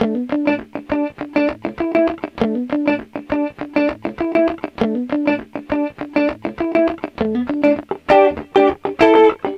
Loops guitares rythmique- 100bpm 2
Guitare rythmique 27